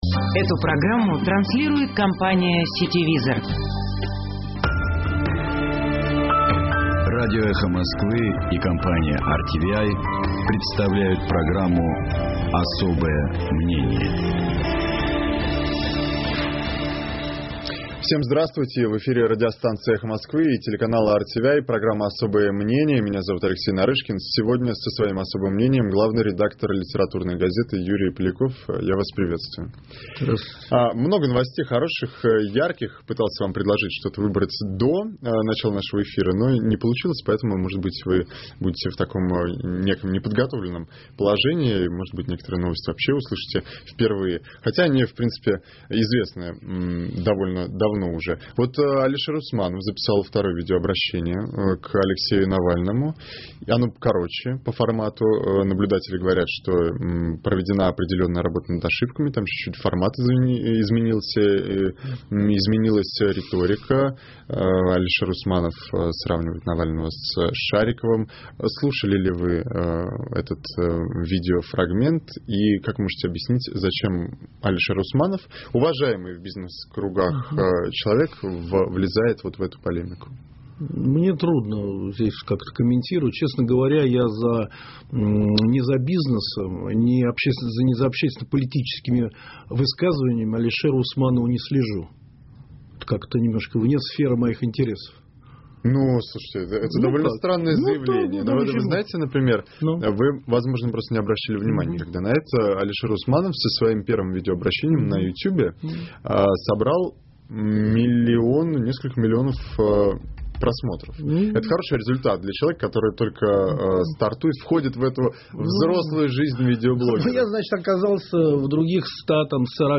В эфире радиостанции «Эхо Москвы» и телеканала RTVi программа «Особое мнение».